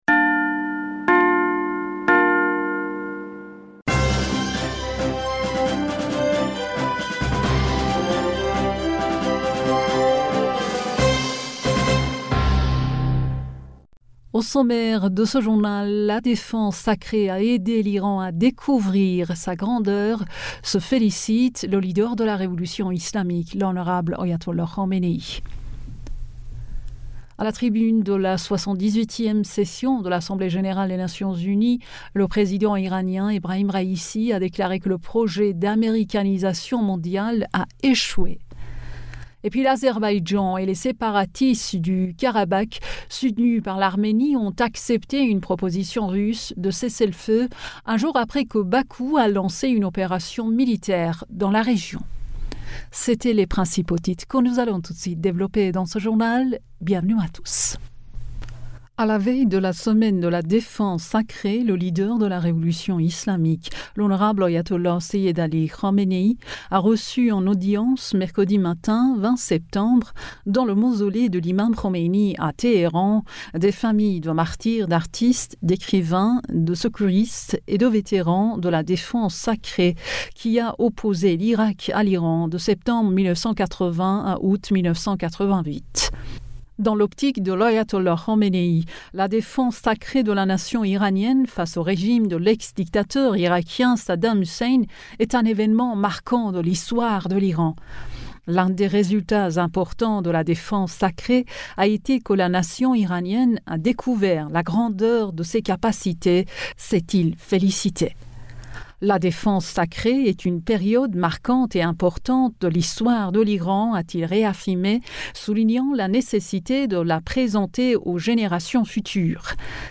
Bulletin d'information du 20 Septembre 2023